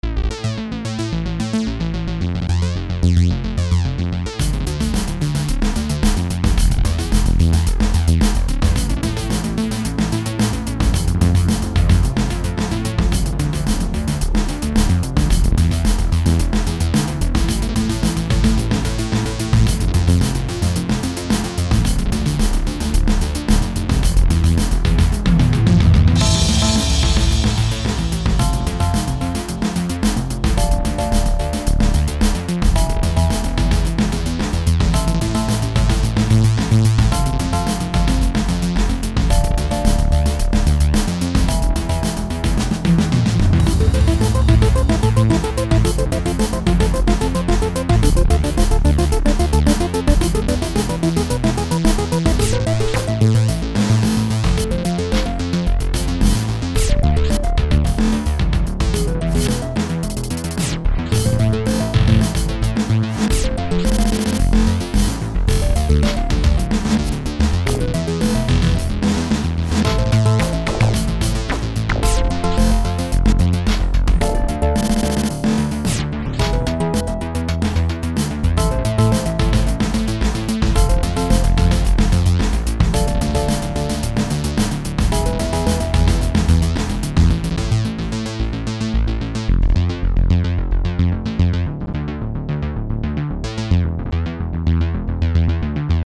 a-harmonic-minor surrealism.mp3